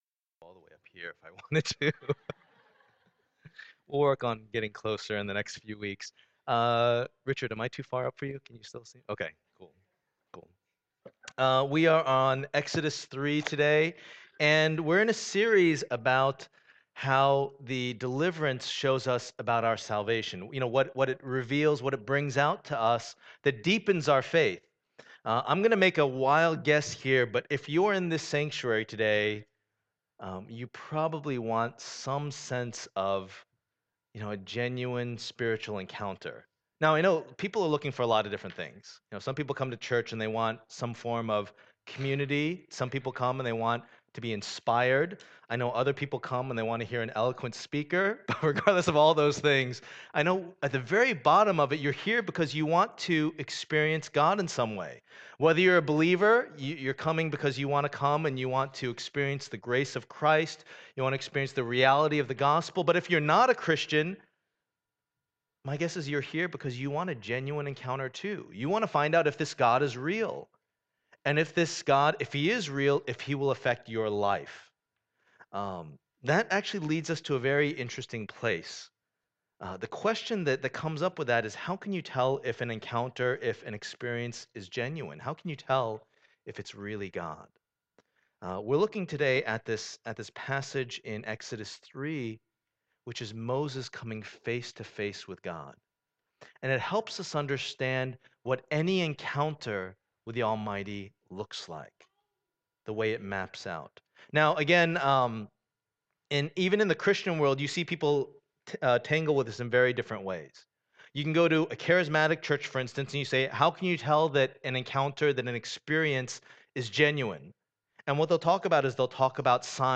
Passage: Exodus 3:1-12 Service Type: Lord's Day